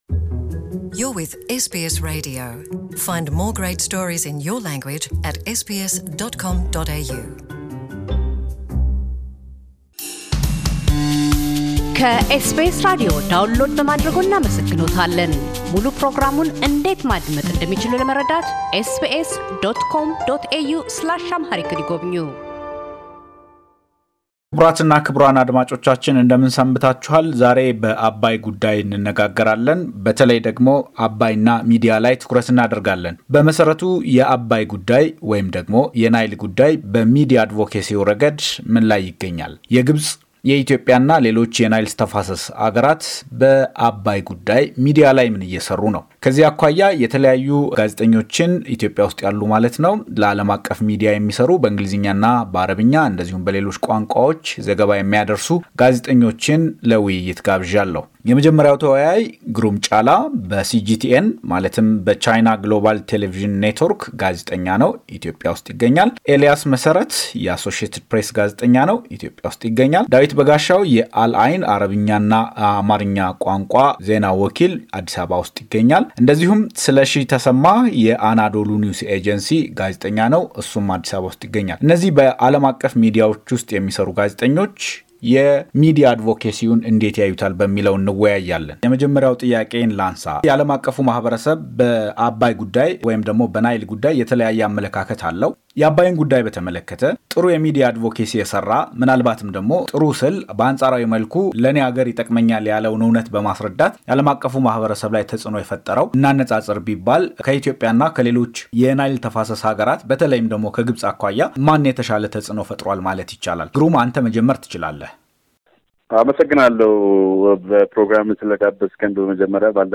ኢትዮጵያውያን ጋዜጠኞቹ የአገራቱን የሕዳሴ ግድብ የሚዲያ አዘጋገቦች ይመዝናሉ።